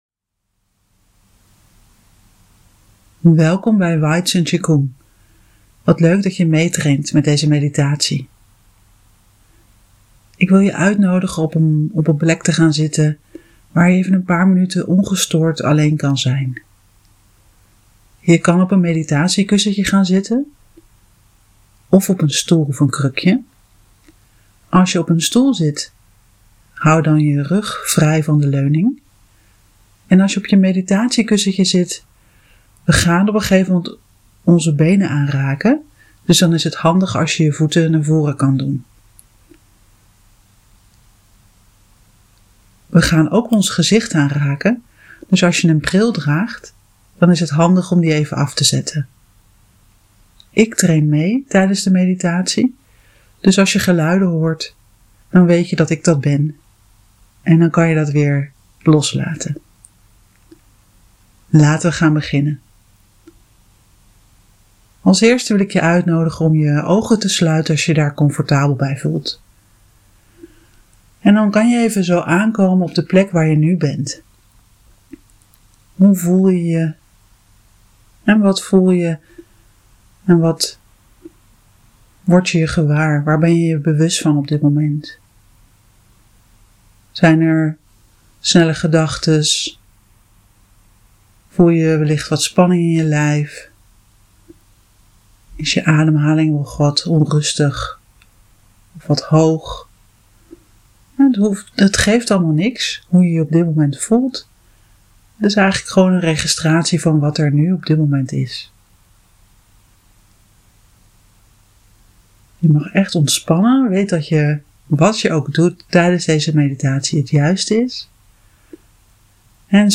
Belichaamde meditatie